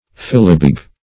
fillibeg - definition of fillibeg - synonyms, pronunciation, spelling from Free Dictionary Search Result for " fillibeg" : The Collaborative International Dictionary of English v.0.48: Fillibeg \Fil"li*beg\, n. A kilt.